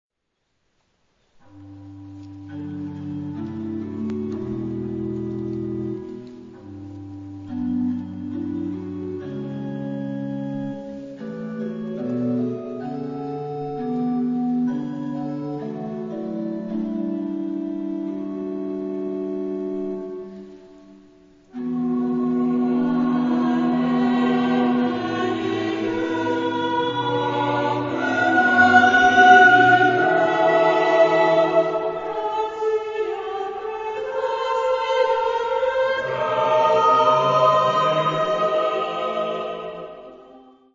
SATB (4 voces Coro mixto) ; Partitura general.
Plegaria. contemporáneo.